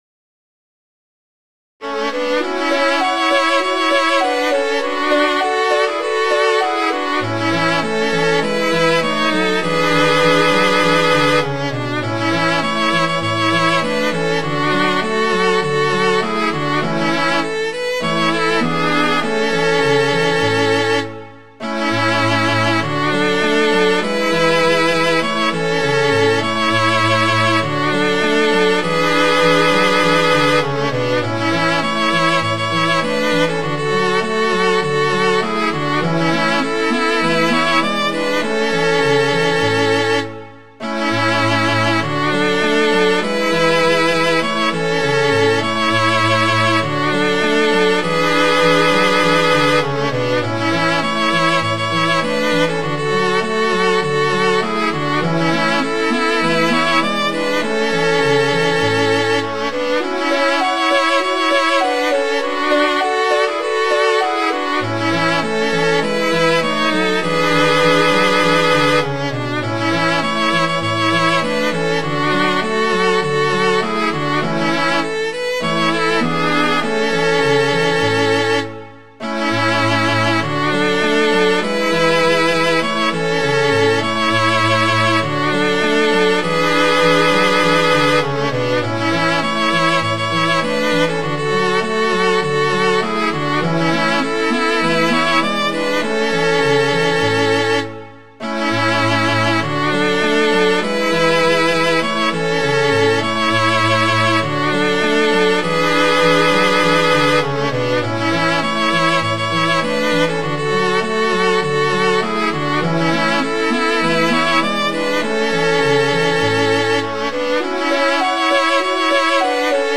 Midi File, Lyrics and Information to Lilly Daler